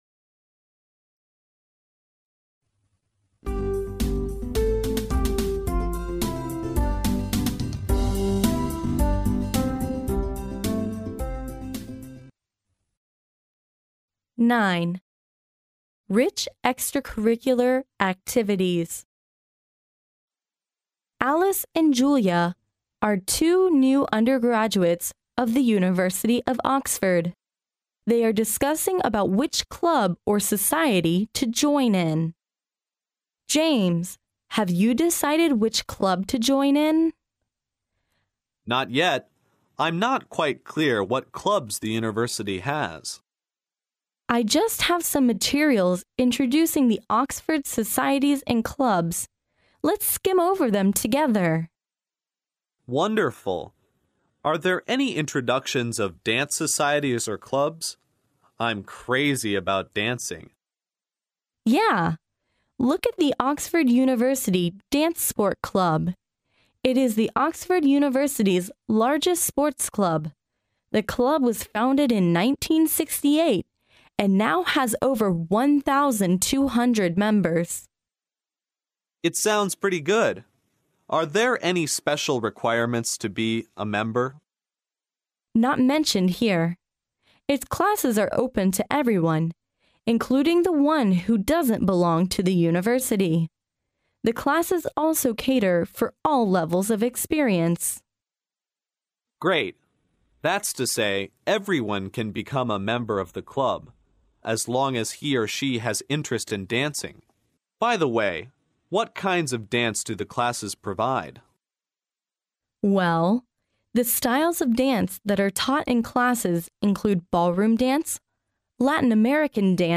牛津大学校园英语情景对话09：丰富多彩的课外活动（mp3+中英）